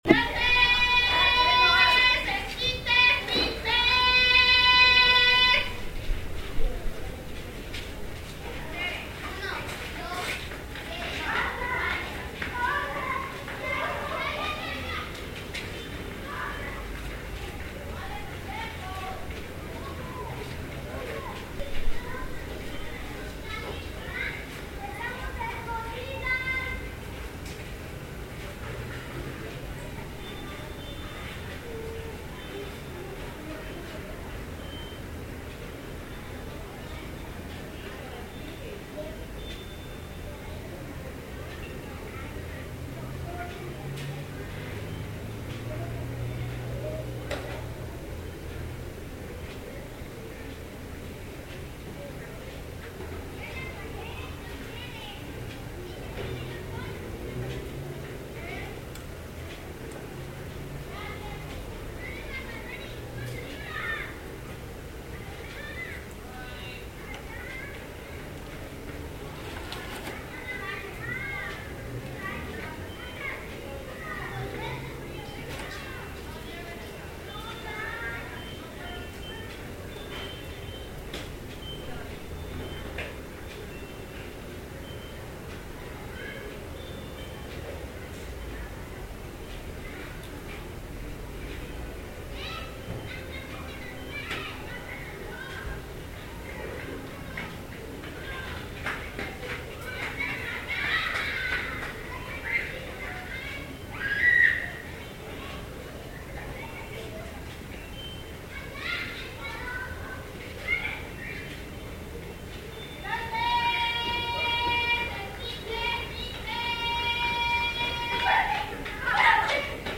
Los gritos, las risas, los pasos, son juegos de niños que nosotros como adultos nos da nostalgia, por ese tiempo que se fue y que siempre pensaremos como los mejores.